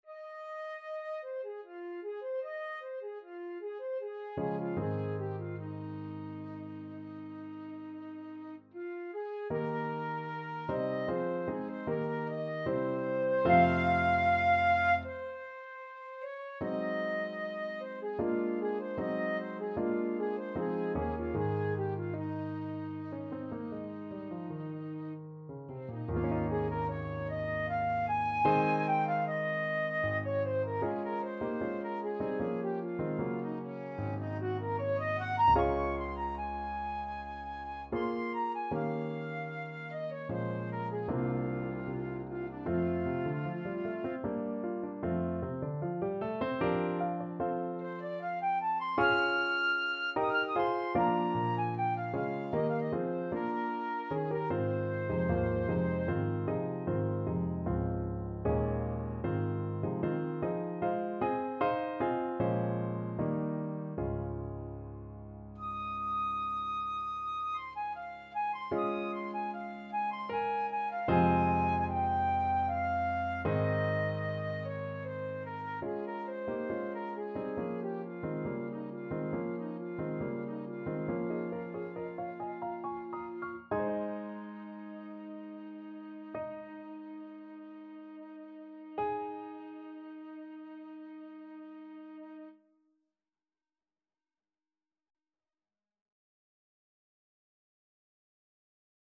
Classical Debussy, Claude The Girl with the Flaxen Hair (Prelude 8) Flute version
FlutePiano
Trs calme et doucement expressif =76
3/4 (View more 3/4 Music)
Ab major (Sounding Pitch) (View more Ab major Music for Flute )
Flute  (View more Intermediate Flute Music)
Classical (View more Classical Flute Music)